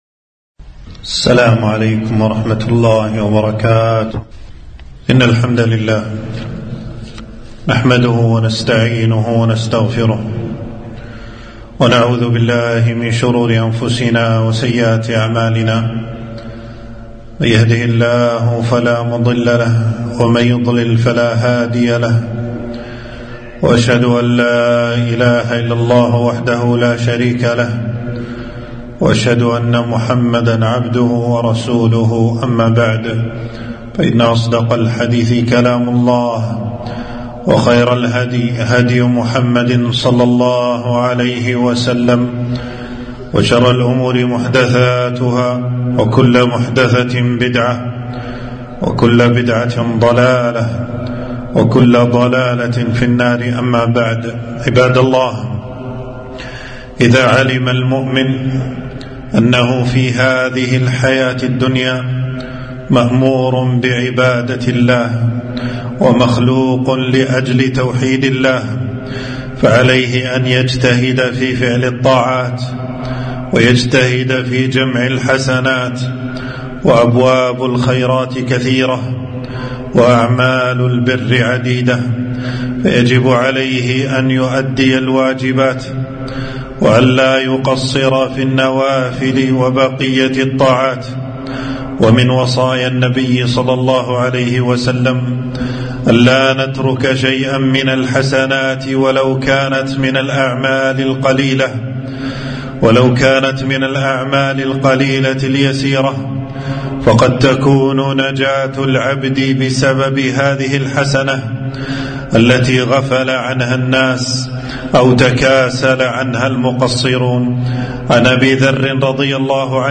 خطبة - لا تحقرن من الإحسان عملاً